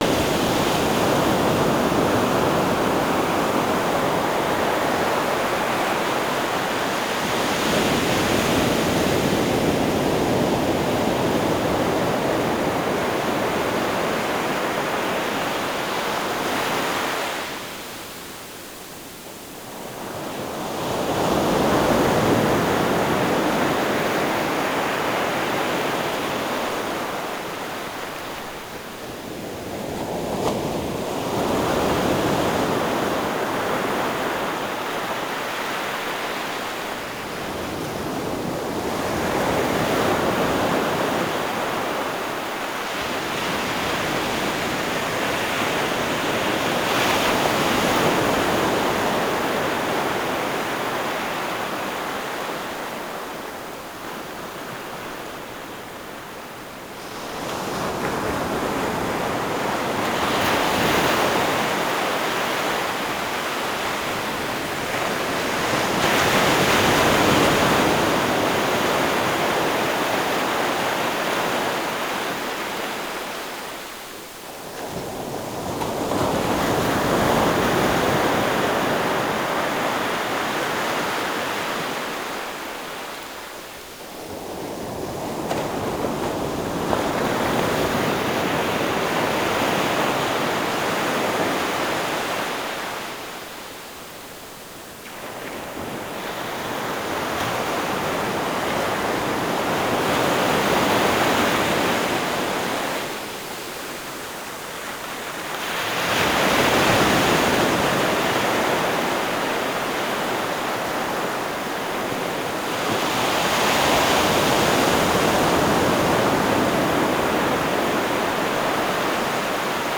WORLD SOUNDSCAPE PROJECT TAPE LIBRARY
China Beach, ocean waves 3:19